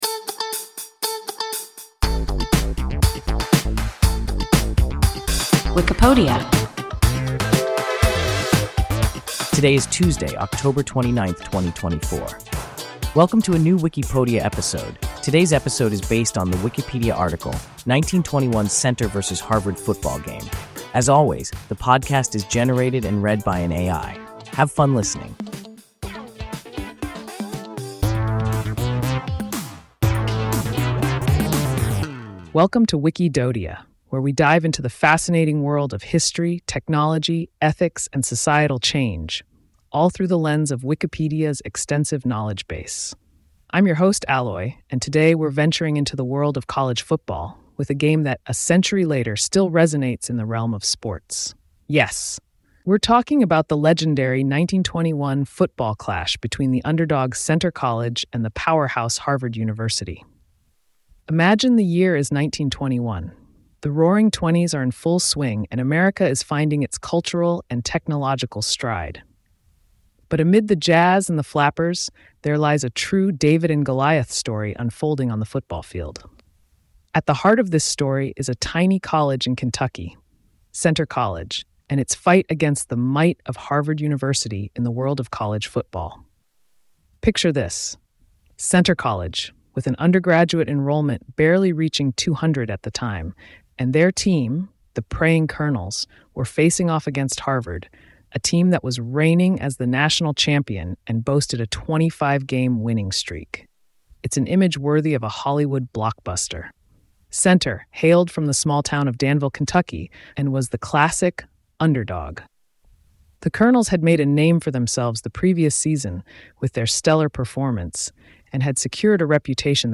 1921 Centre vs. Harvard football game – WIKIPODIA – ein KI Podcast